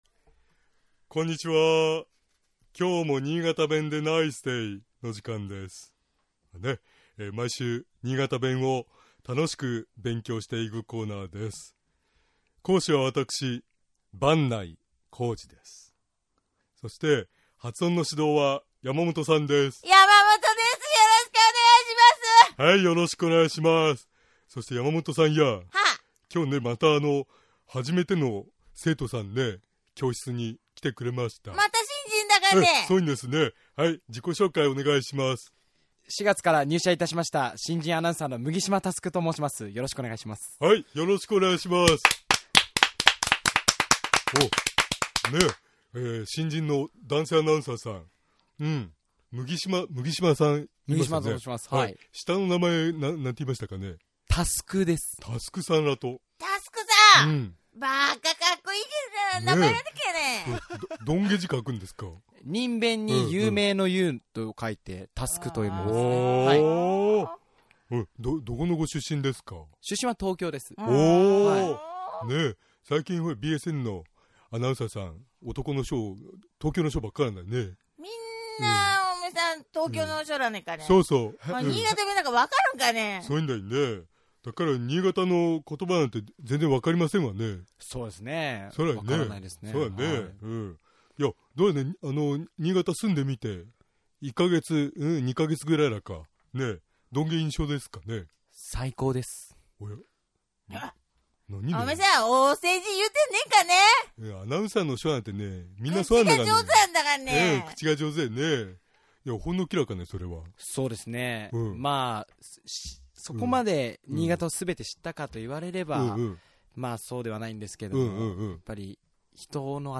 今回のポイントは、気を付けたい苗字のアクセントです。 新潟弁では、「石山」「丸山」など「００山」という苗字に、 敬称の「さん」を付けて呼ぶ場合、 共通語とは違うアクセントになる傾向があります。 この他、「清水」「原」という苗字も、「～さん」が付くと、 新潟では独特のアクセントで発音される事も多いようです。